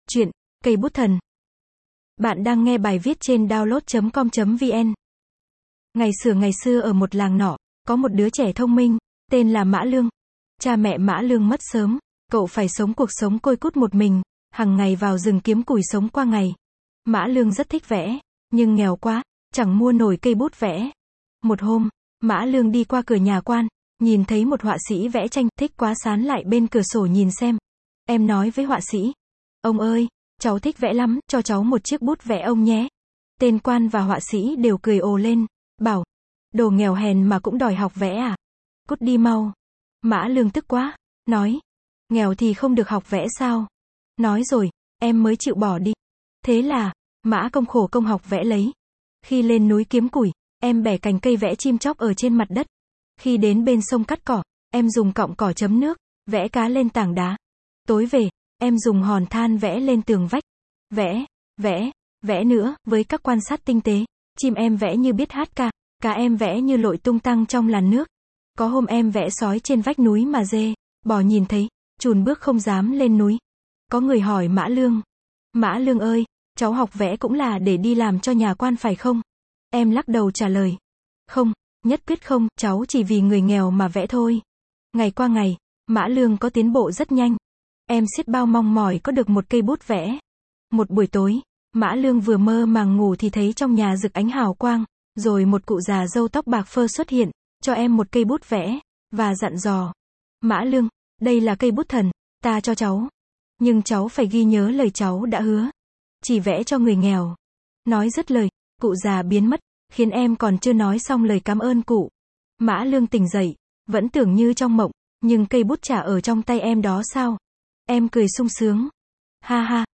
Sách nói | Truyện cổ tích cây bút thần